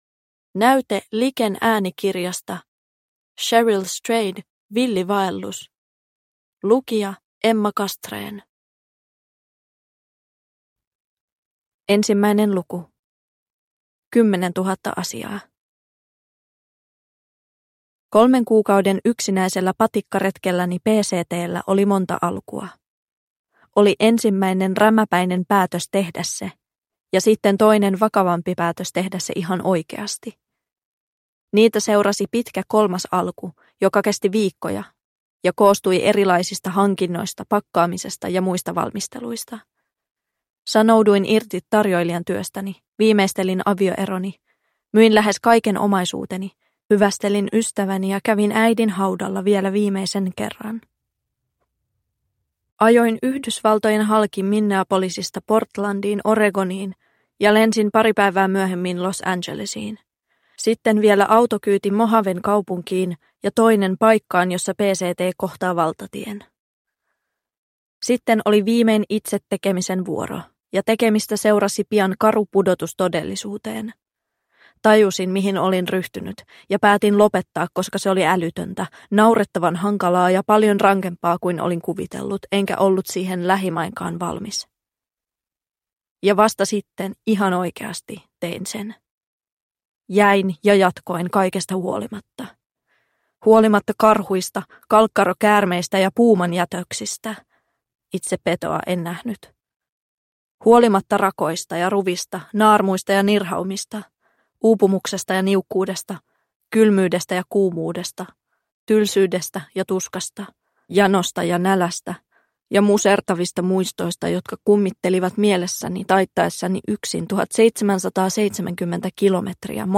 Villi vaellus – Ljudbok